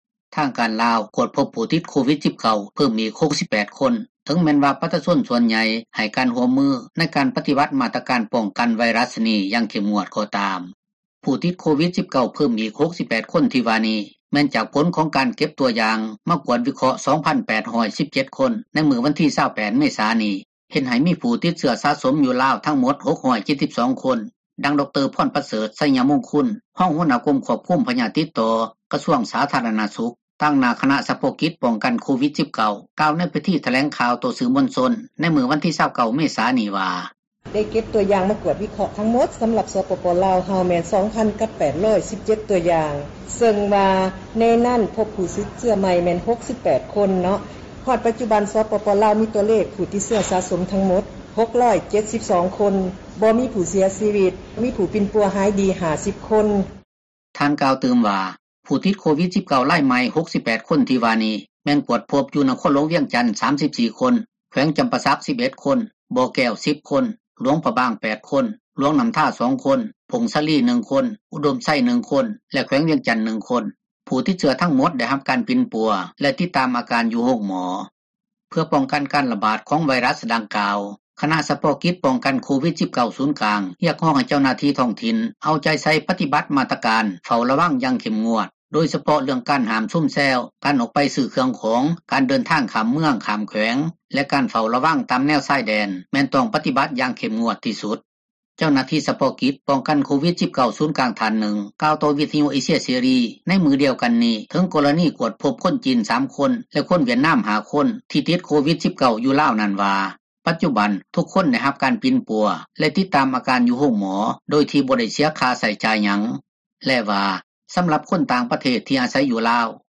ຜູ້ຕິດໂຄວິດ-19 ເພີ່ມອີກ 68 ຄົນທີ່ວ່ານີ້ ແມ່ນຈາກຜົລຂອງການເກັບຕົວຢ່າງ ມາກວດວິເຄາະ 2,817 ຄົນໃນມື້ວັນທີ 28 ເມສາ ນີ້, ເຮັດໃຫ້ມີຜູ້ຕິດເຊື້ອສະສົມ ຢູ່ລາວ ທັງໝົດ 672 ຄົນ ດັ່ງທີ່ ດຣ. ພອນປະເສີດ ໄຊຍະມຸງຄຸນ ຮອງຫົວໜ້າກົມຄວບຄຸມ ພຍາດຕິດຕໍ່ ກະຊວງສາທາຣະນະສຸຂ, ຕາງໜ້າຄະນະສະເພາະກິດ ປ້ອງກັນໂຄວິດ-19 ກ່າວໃນພິທີຖແລງ ຂ່າວຕໍ່ສື່ມວນຊົນ ໃນມື້ວັນທີ 29 ເມສາ ນີ້ວ່າ: